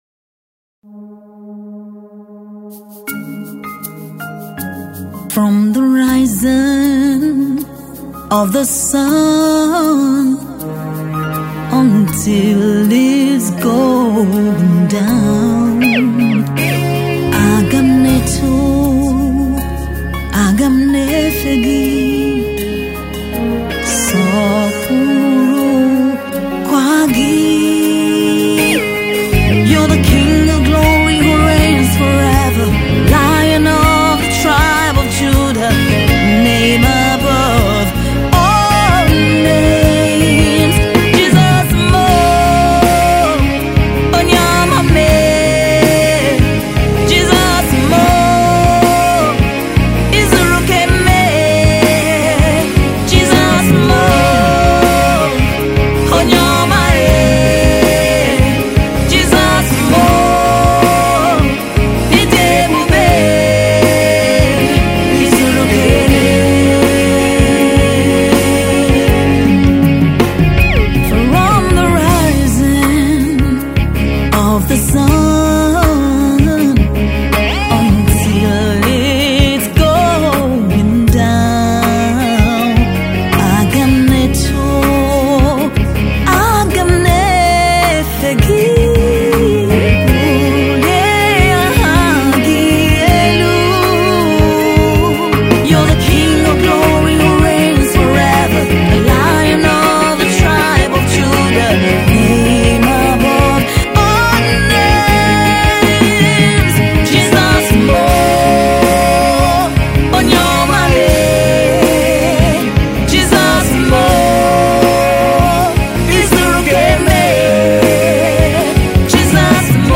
worship artiste